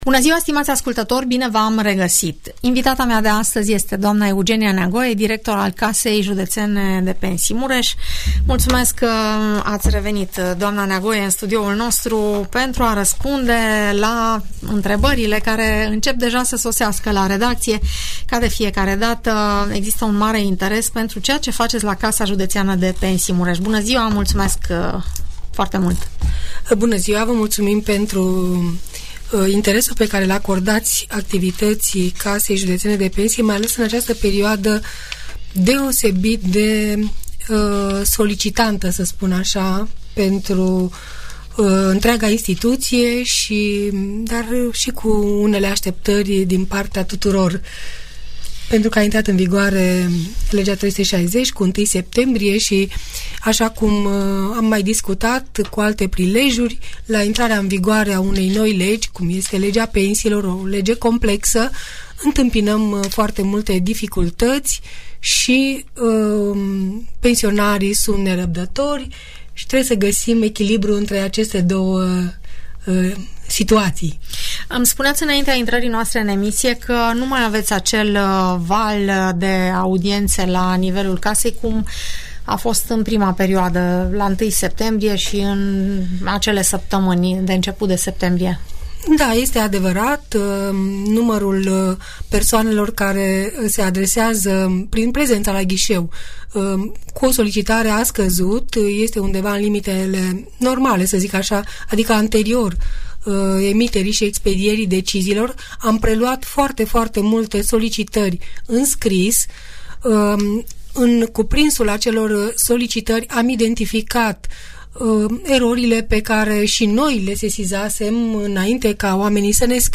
între ascultătorii Radio Tg. Mureș și directorul Casei Județene de Pensii Mureș, doamna Eugenia Neagoe.